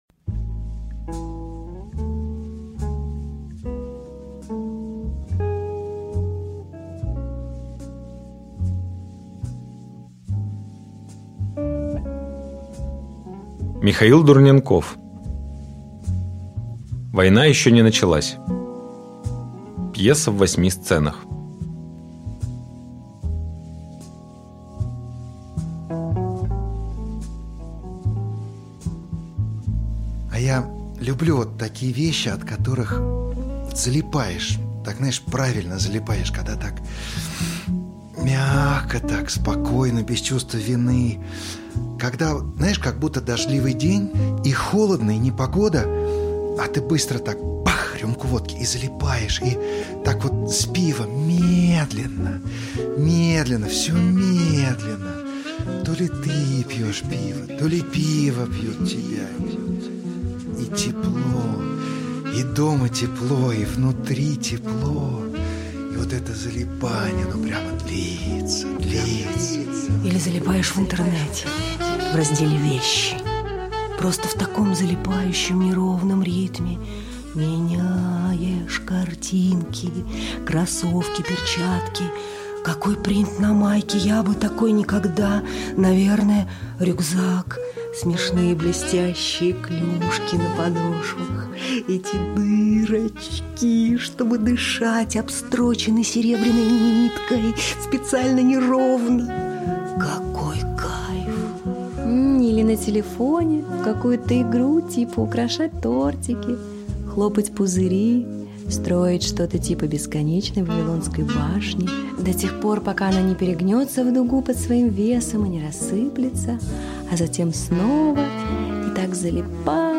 Аудиокнига Война ещё не началась | Библиотека аудиокниг